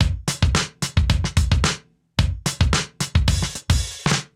Index of /musicradar/sampled-funk-soul-samples/110bpm/Beats